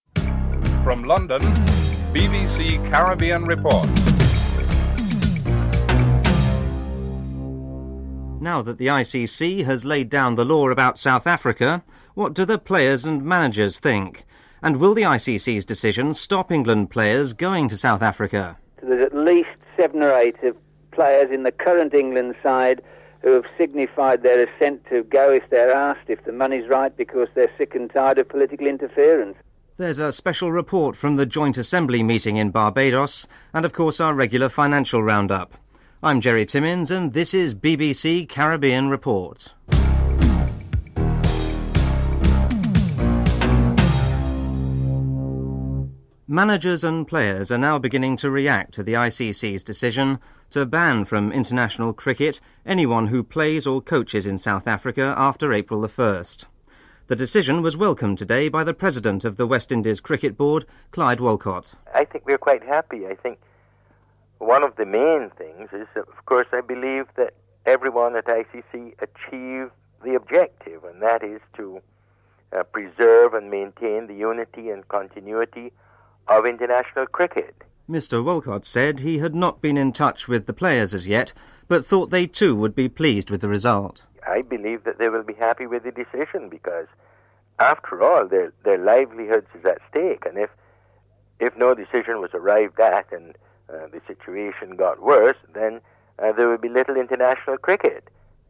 1. Headlines (00:00-00:45)
3. Financial News (09:29-10:08)